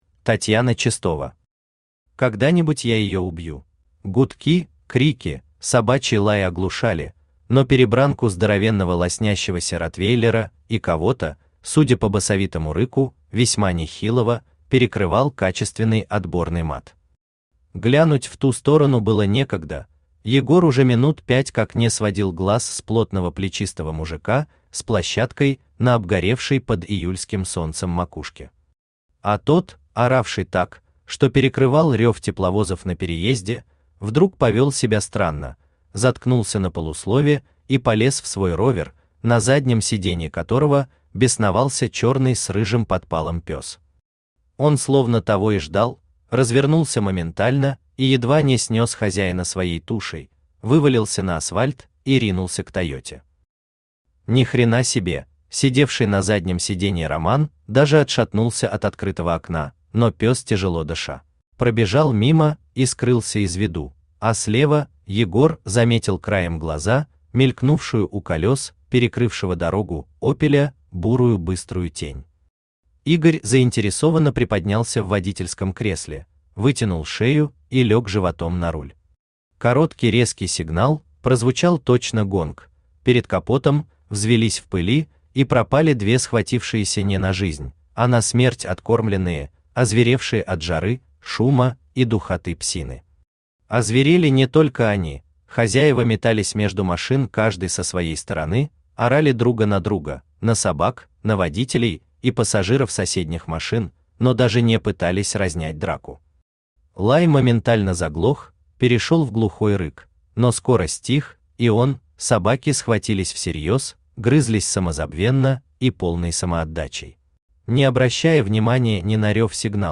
Aудиокнига Когда-нибудь я ее убью Автор Татьяна Чистова Читает аудиокнигу Авточтец ЛитРес.